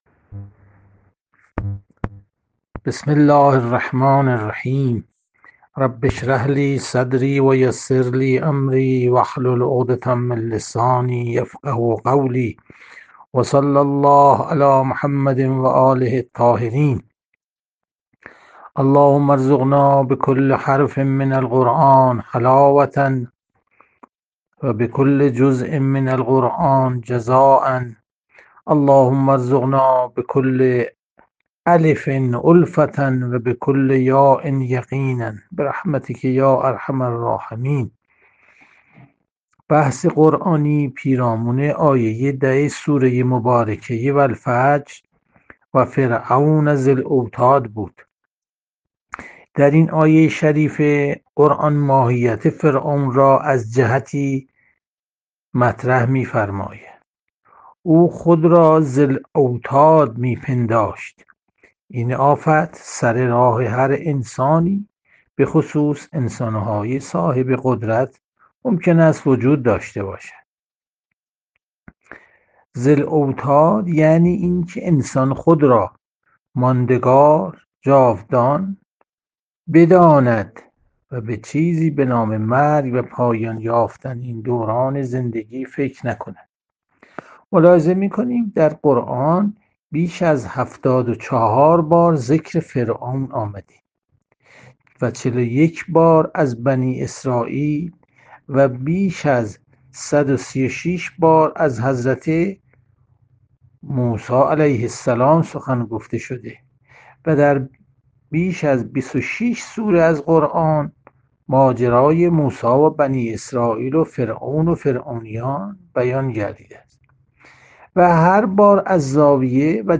جلسه مجازی هفتگی قرآن، سوره فجر، ۱۸ مهر ۱۴۰۰